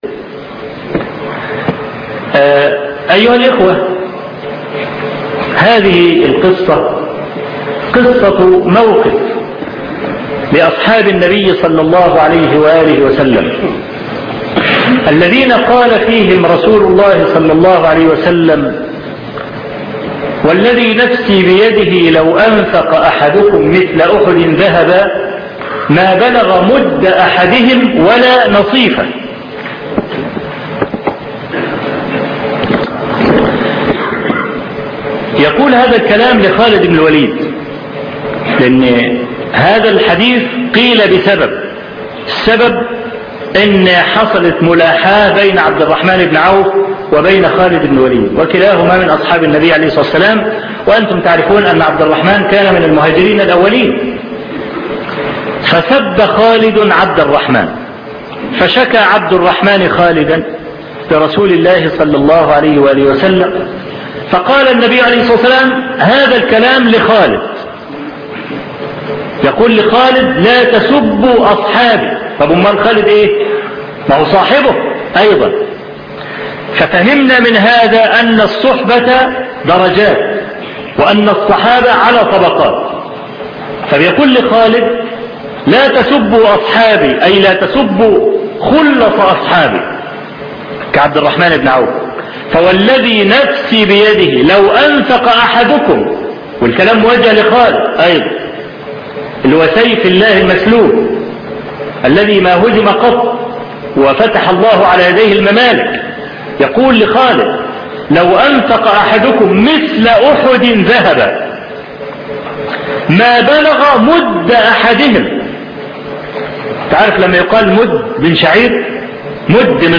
حول حديث لاَ تَسُبُّوا أَصْحَابِي درس نادر - الشيخ أبو إسحاق الحويني